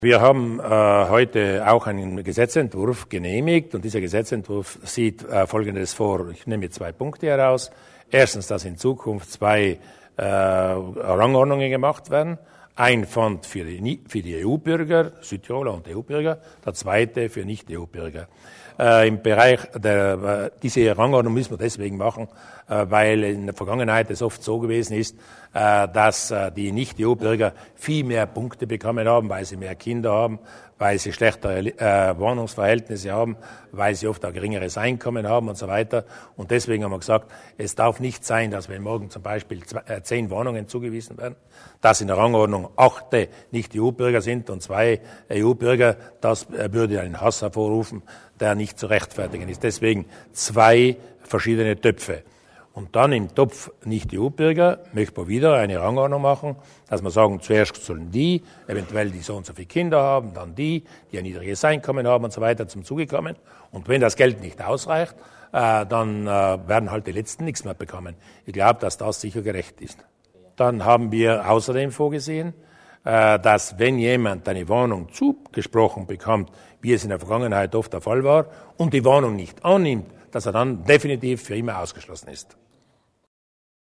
Landeshauptmann Durnwalder über die getrennten Ranglisten im sozialen Wohnbau